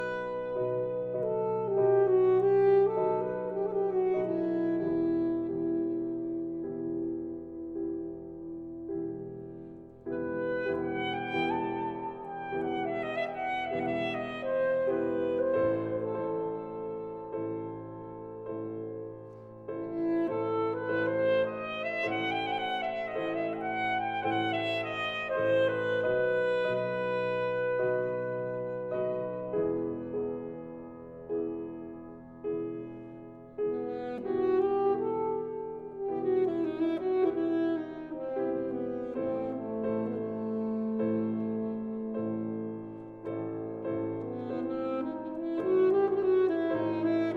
Saxophone et Piano